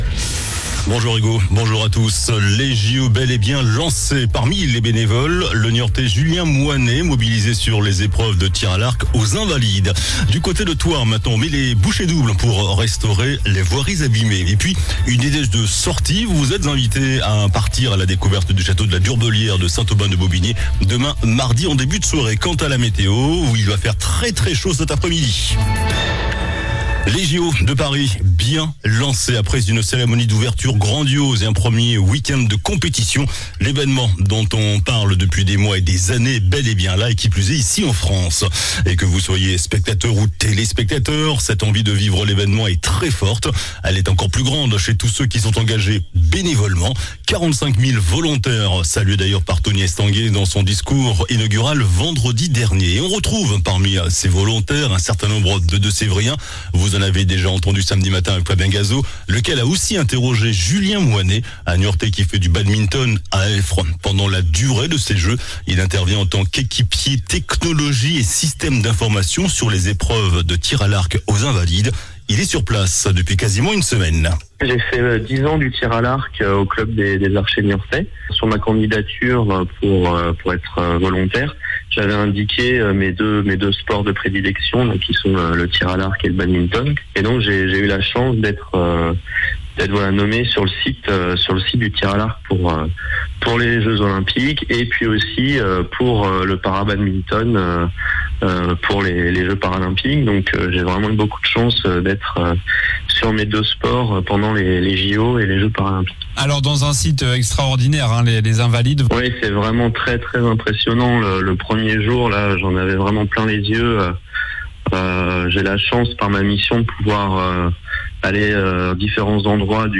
JOURNAL DU LUNDI 29 JUILLET ( MIDI )